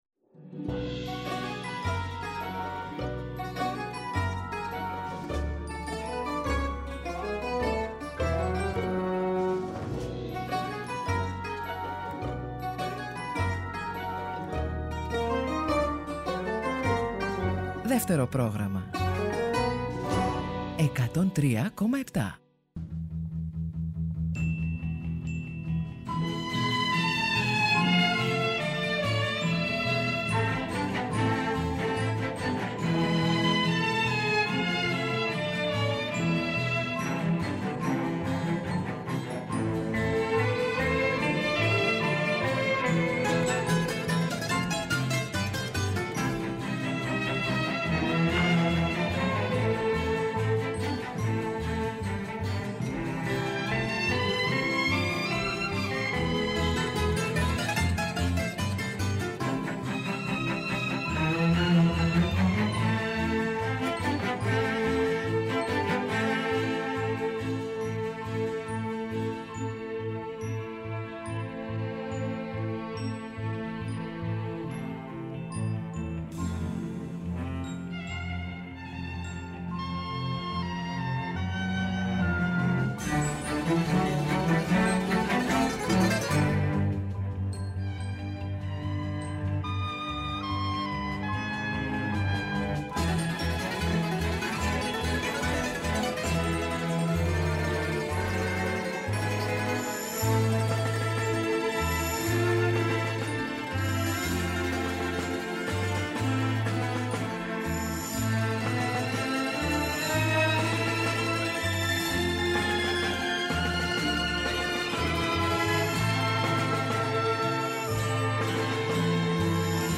Βόλτες στις μελωδίες, τους ήχους και τους στίχους από όλες τις εποχές του ελληνικού τραγουδιού, διανθισμένες με παρουσιάσεις νέων δίσκων, κινηματογραφικών εντυπώσεων, αλλά και ζεστές κουβέντες με καλλιτέχνες από τη θεατρική επικαιρότητα.